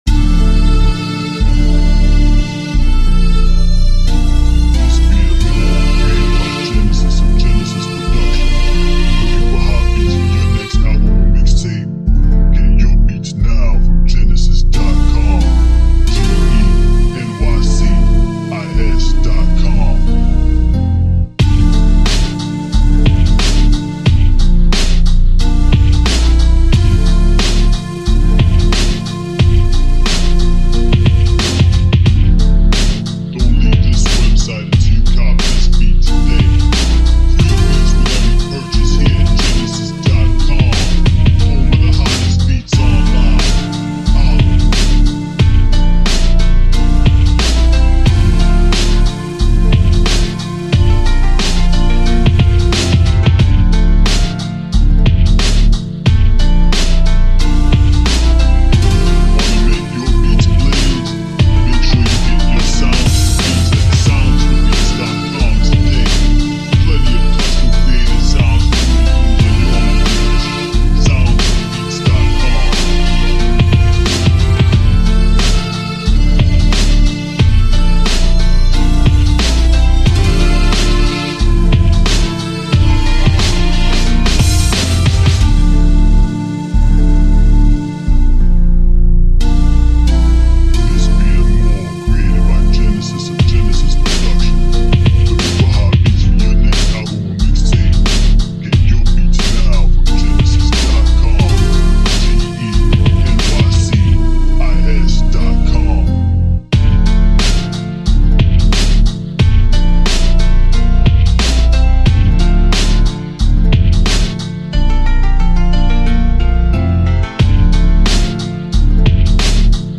Somber Flute Story Rap Beat